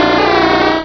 -Replaced the Gen. 1 to 3 cries with BW2 rips.
seel.aif